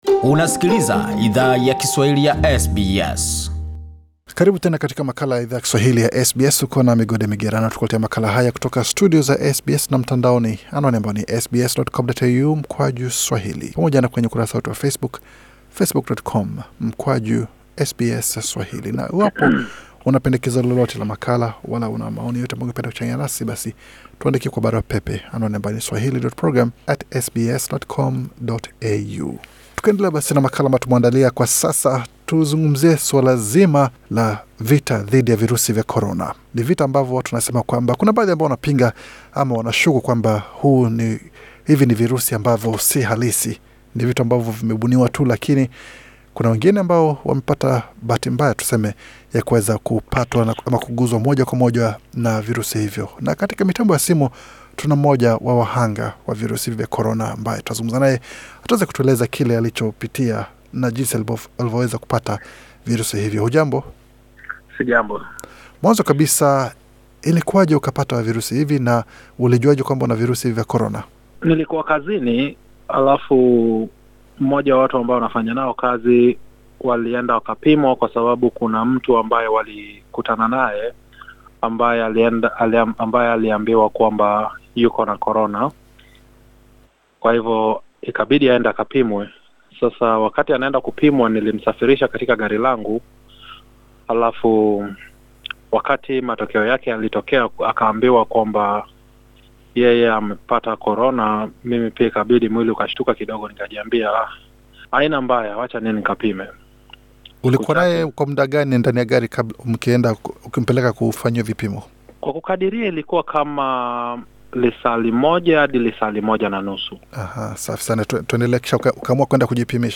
Mwanaume mmoja aliye ambukizwa virusi vya COVID-19 jijini Melbourne, alichangia uzoefu wake waku kabiliana nakushinda janga hilo katika mazungumzo maalum na Idhaa ya Kiswahili ya SBS.